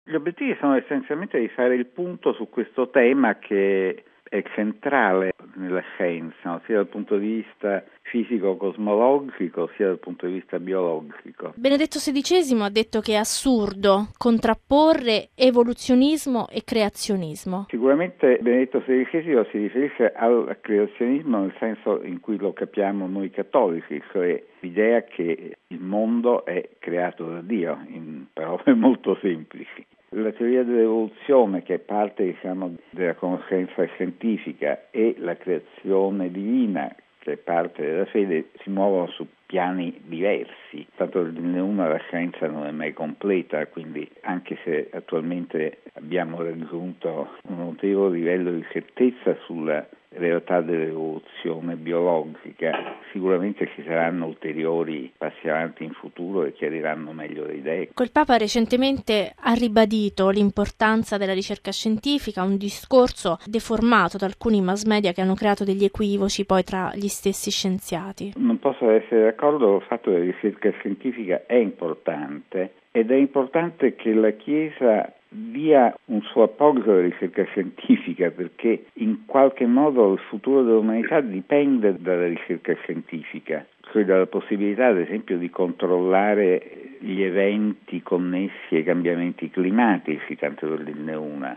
ha intervistato il prof. Nicola Cabibbo, presidente della Pontificia Accademia delle Scienze e docente di Fisica delle particelle all’Università La Sapienza di Roma: